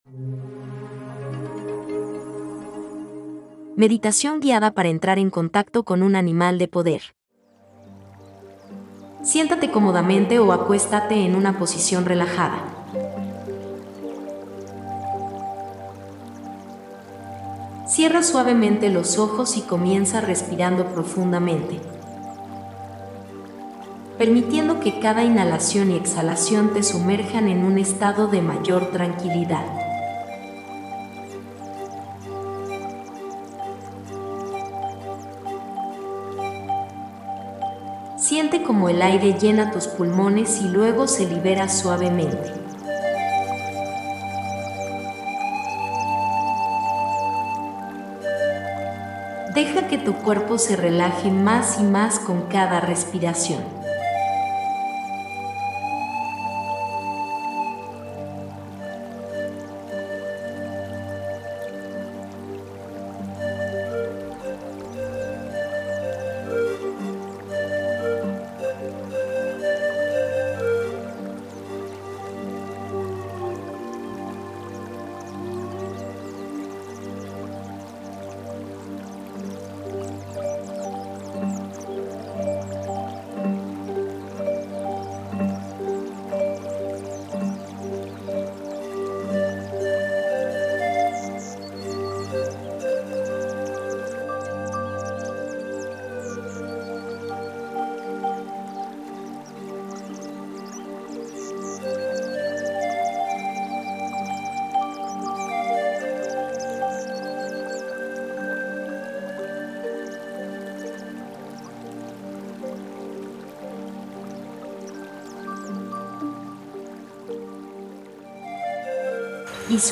Elige si prefieres escuchar el audio de la meditación o ver el video